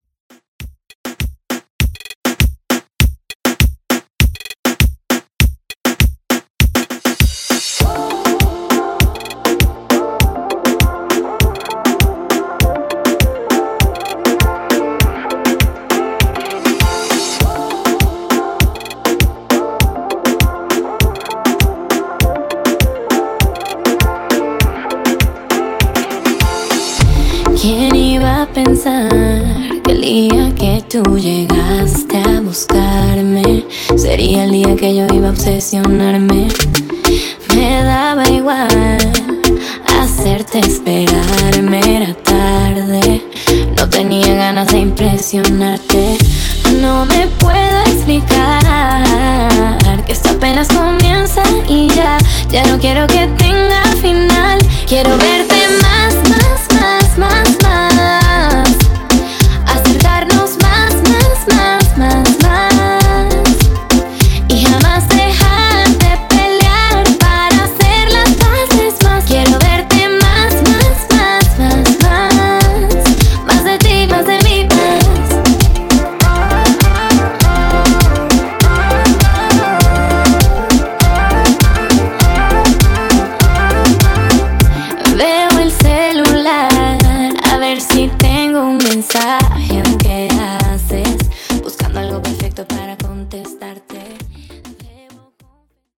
In-Outro Reton)Date Added